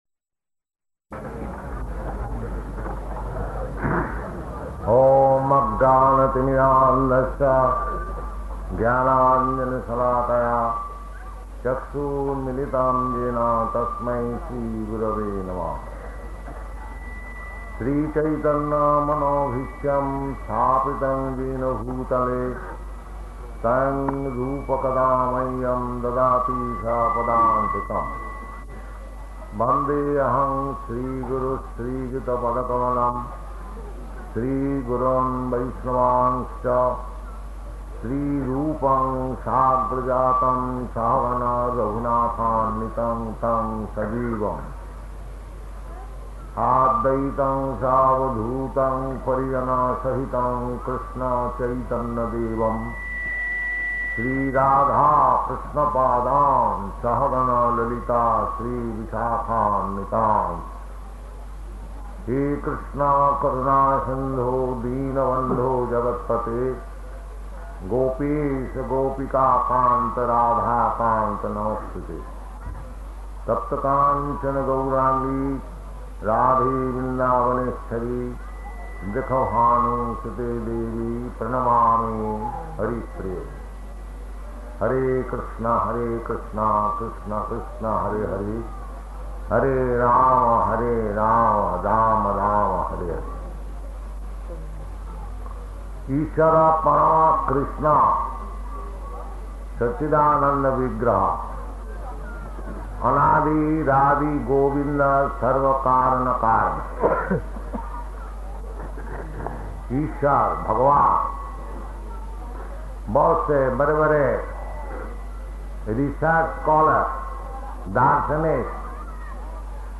Lecture in Hindi
Lecture in Hindi --:-- --:-- Type: Lectures and Addresses Dated: December 29th 1970 Location: Surat Audio file: 701229LE-SURAT.mp3 Prabhupāda: [recites prayers and delivers talk] [Hindi] Lecture in Hindi Lecture in Hindi